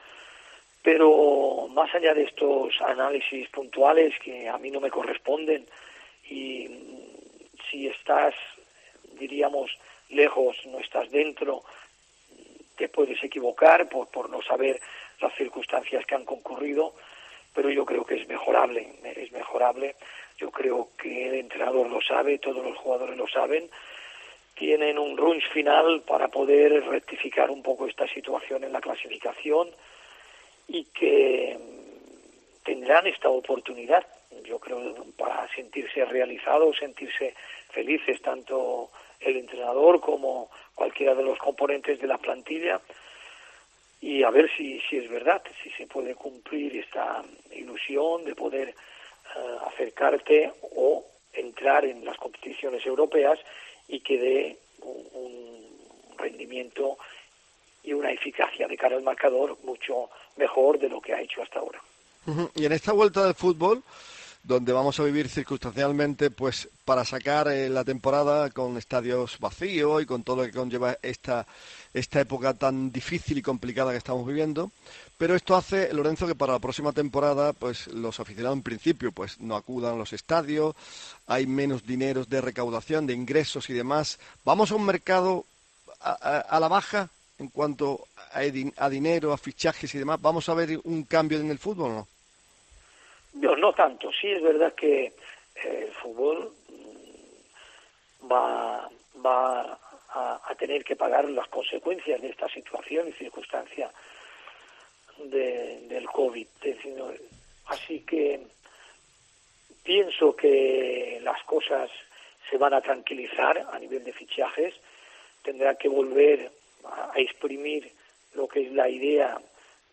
PRIMERA PARTE DE LA ENTREVISTA DE LORENZO SERRA FERRER EN COPE MÁS SEVILLA